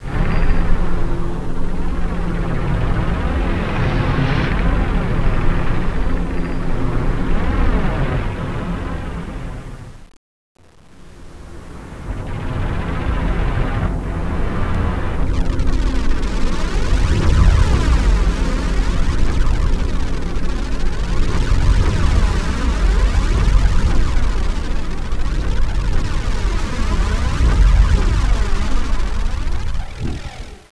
live-mitschnitte aus den inatallationen in der bramfelder straße, 8 bit 22 khz 30 sec.: